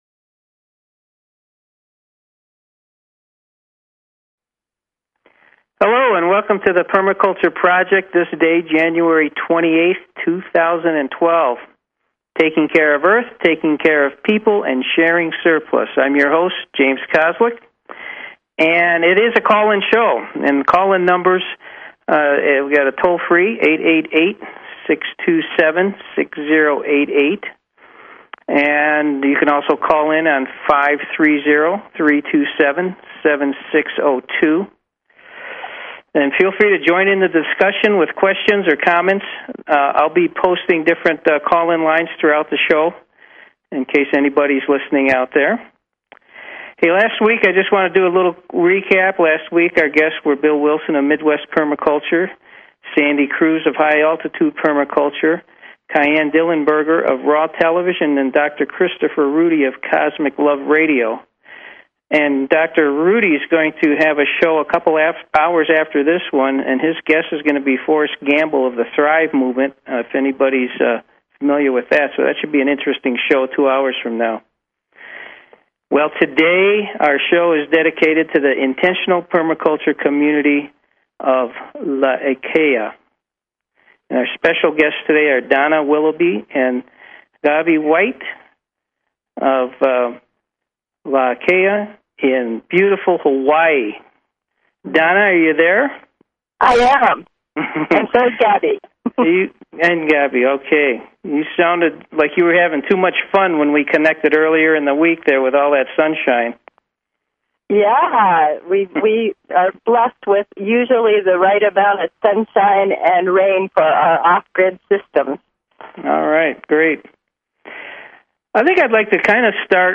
Talk Show Episode, Audio Podcast, Permaculture_Project and Courtesy of BBS Radio on , show guests , about , categorized as